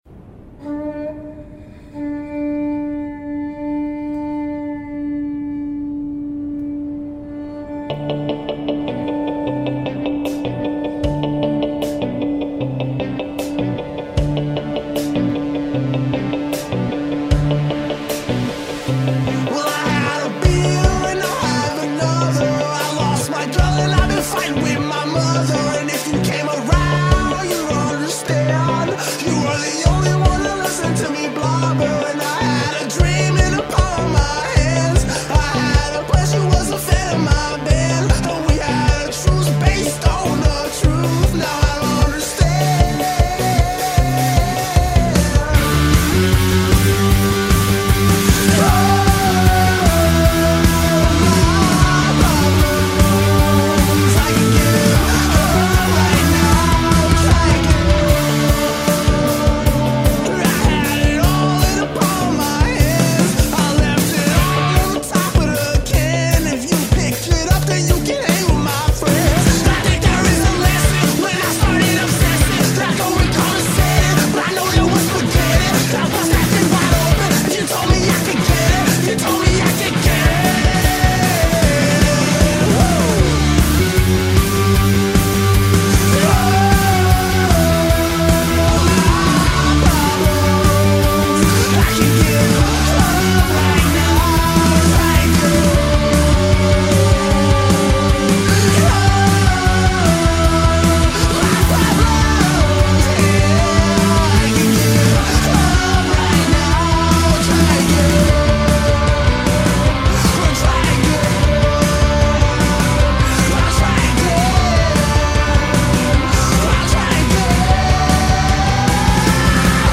silly irreverence and party-rockin’ spirit
blend pop/punk songwriting with a hip-hop swagger